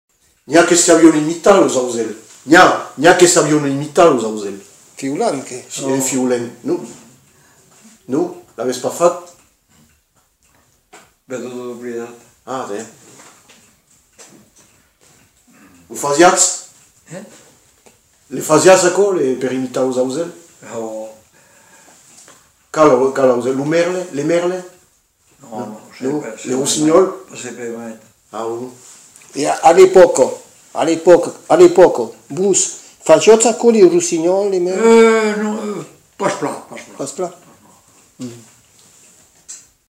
Aire culturelle : Lauragais
Lieu : Le Faget
Genre : témoignage thématique
Descripteurs : oiseau ; imitation d'un animal